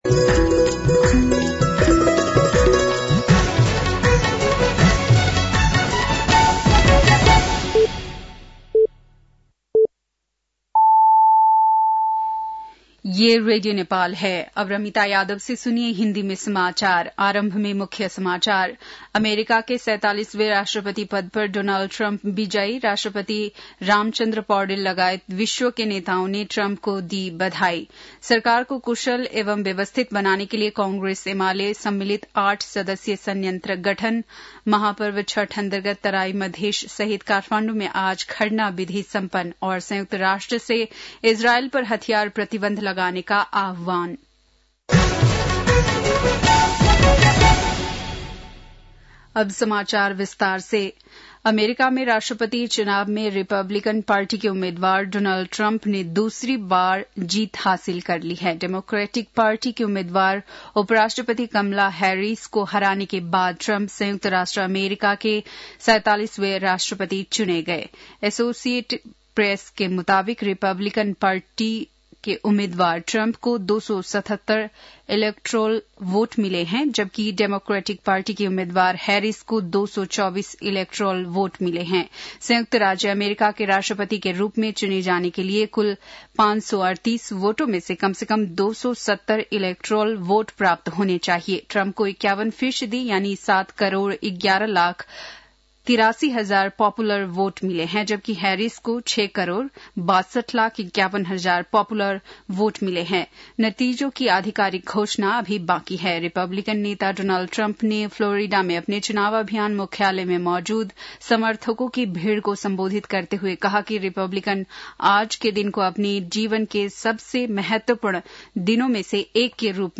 बेलुकी १० बजेको हिन्दी समाचार : २२ कार्तिक , २०८१
10-PM-Hindi-NEWS-7-21.mp3